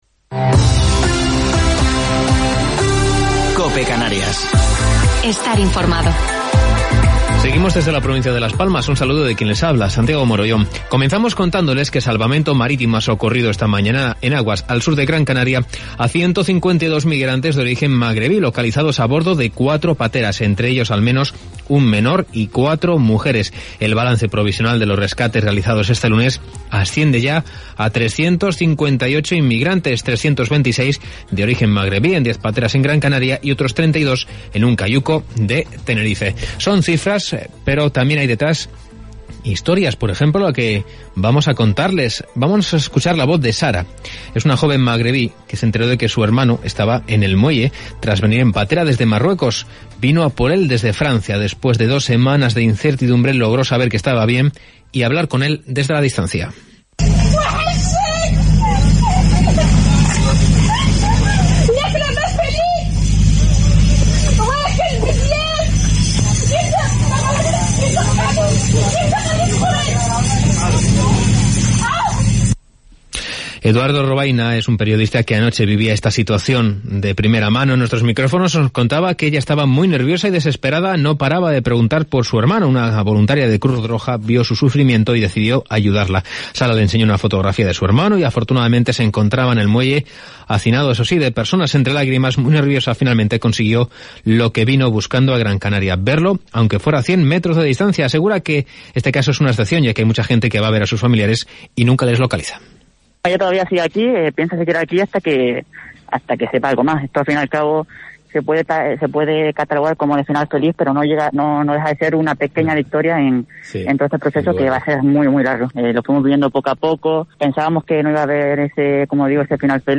Informativo local 17 de Noviembre del 2020